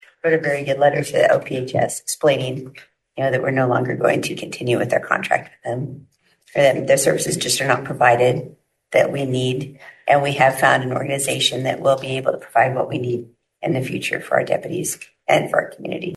Undersheriff Loraine Shore told commissioners the county has run into, in her words, “a lack of communication or responsiveness to discuss service issues…”